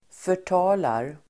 Uttal: [för_t'a:lar]